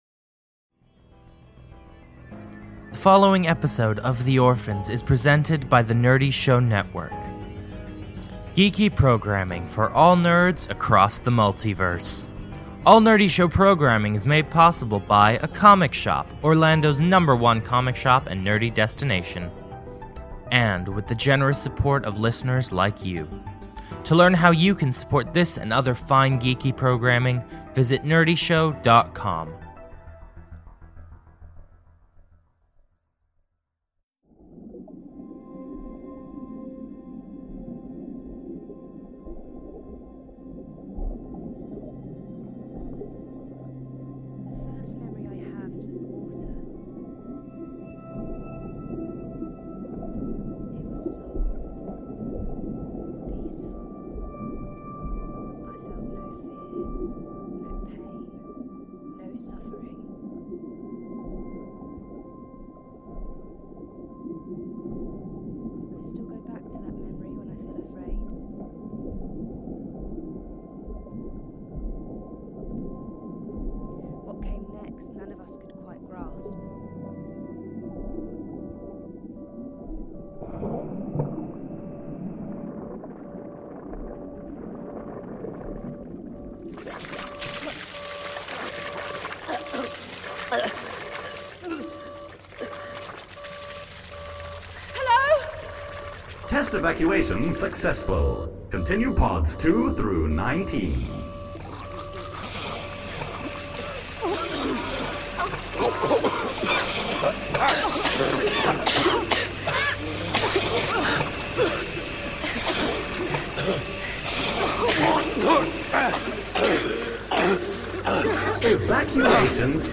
The Orphans is a cinematic audio drama chronicling the castaways of downed starship, The Venture - Stranded on a hostile planet, struggling to remember how they arrived and who they are.